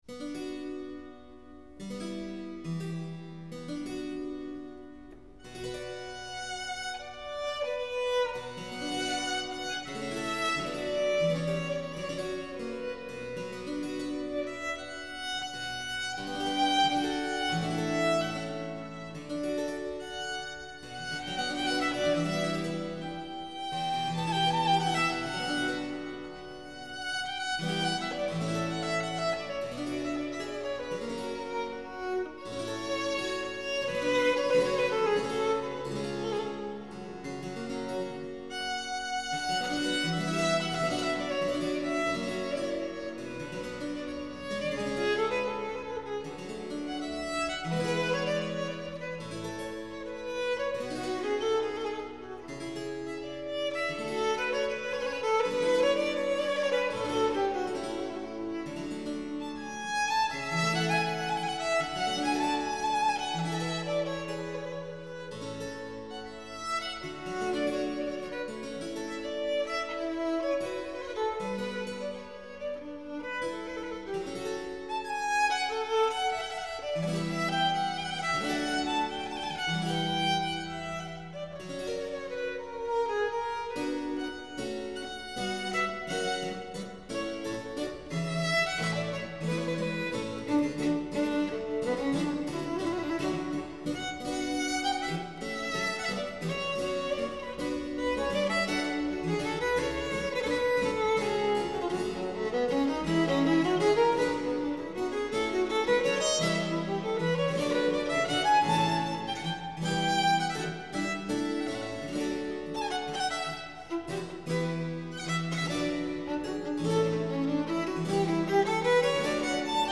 violin
harpsichord Recorded live at the Berkeley Early Music Festival